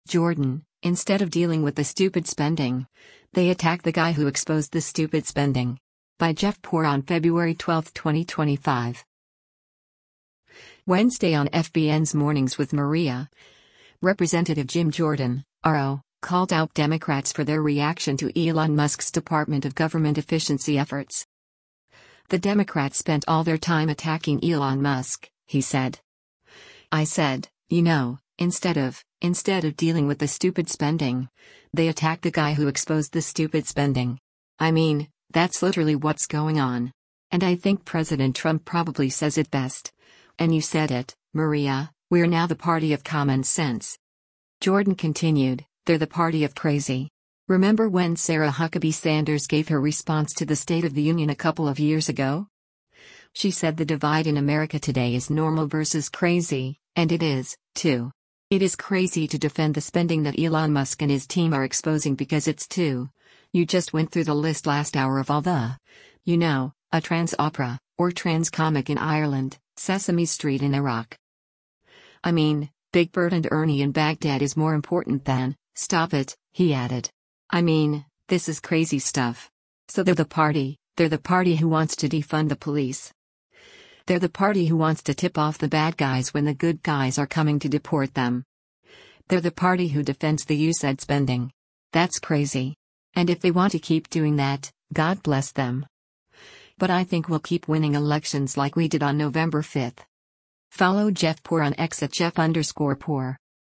Wednesday on FBN’s “Mornings with Maria,” Rep. Jim Jordan (R-OH) called out Democrats for their reaction to Elon Musk’s Department of Government Efficiency efforts.